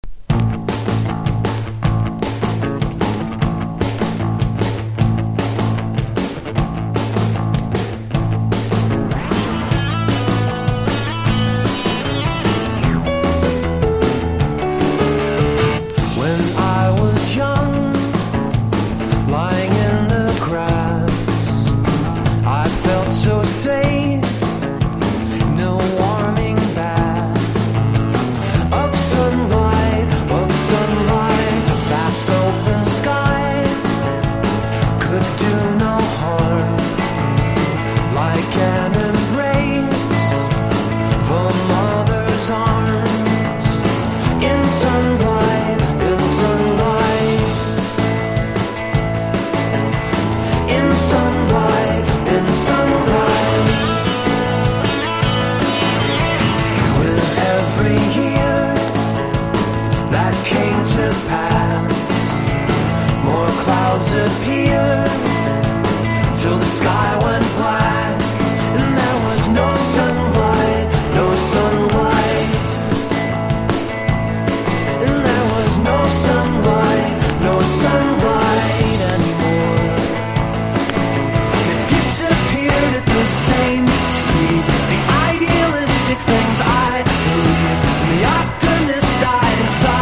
プログレ的なダイナミズムやポストロック的なアプローチを覗かせながらも、ベーシックなバンドサウンドに回帰。